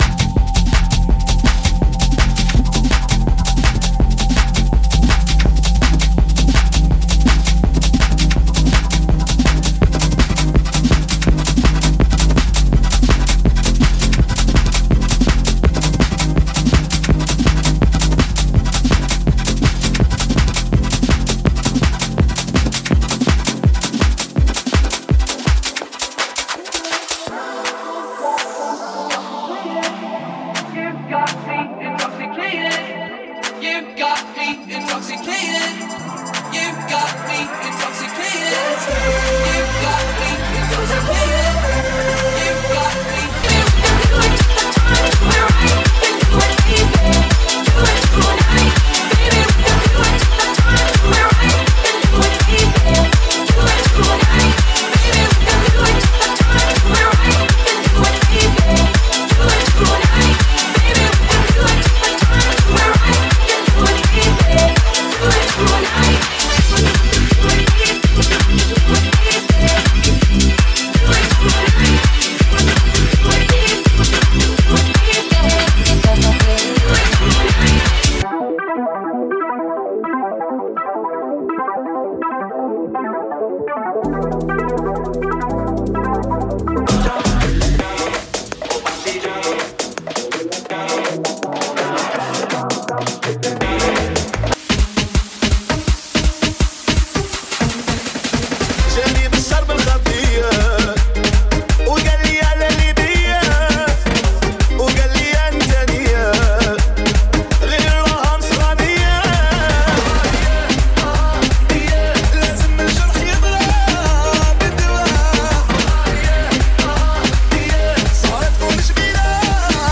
(32 count phrased) 160 BPM
dance, cardio, aerobics, Fitness…
60 minutes Tempo: 160 BPM Marque